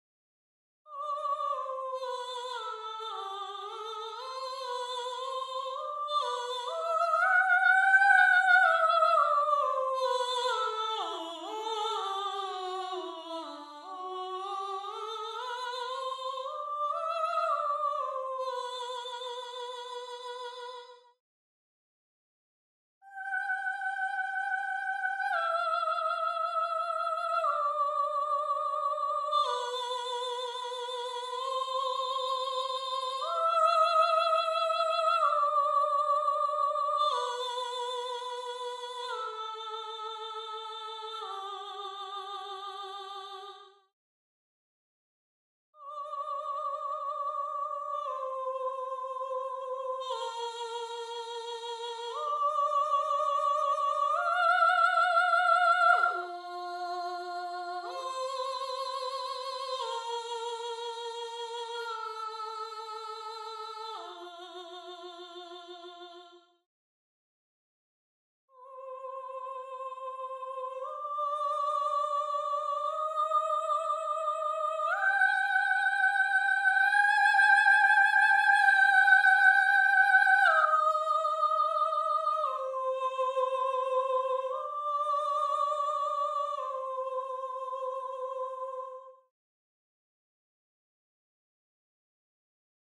1. SOPRANO (Soprano/Soprano)
gallon-v3s3-20-Soprano_0.mp3